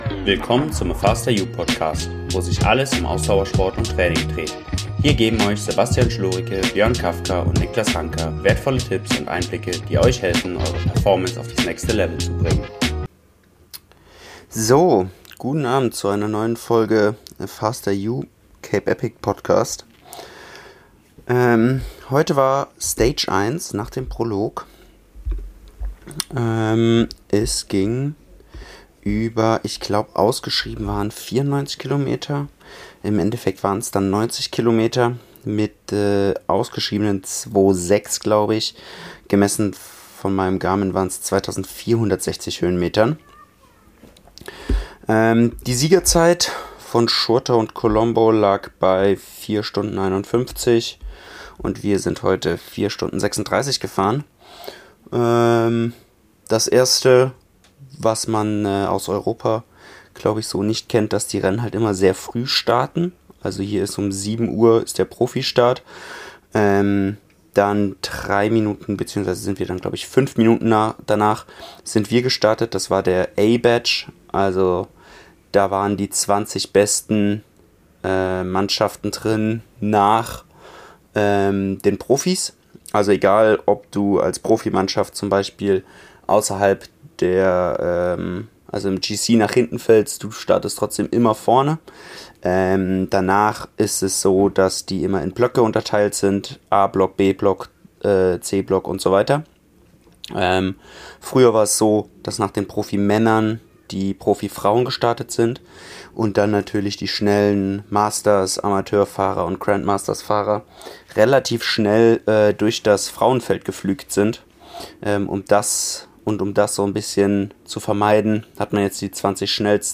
Einen kleinen Einblick in das Heutige Rennen. Entschuldigung für die vielen EHMS und Denkpausen...das wird sicherlich nur noch mehr umso länger das Rennen wird.